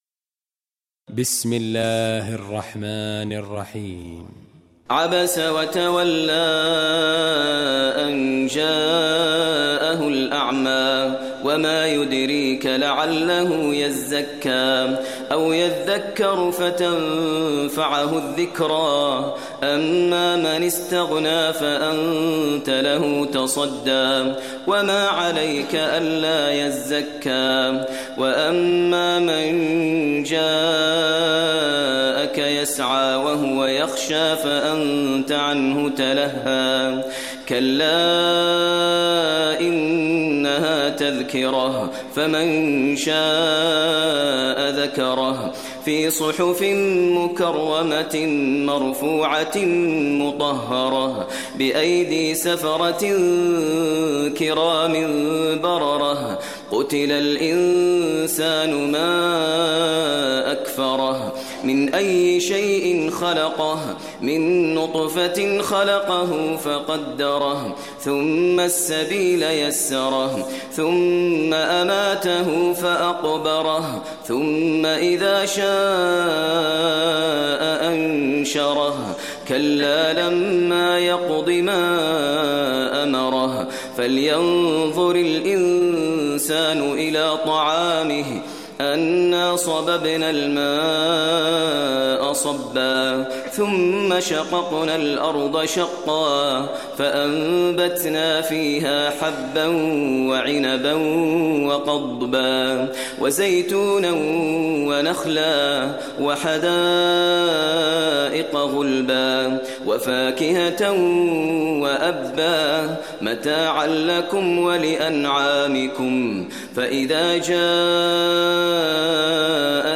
Surah Abasa Recitation by Maher al Mueaqly
Surah Abassa, listen online mp3 tilawat / recitation in Arabic recited by Imam e Kaaba Sheikh Maher al Mueaqly.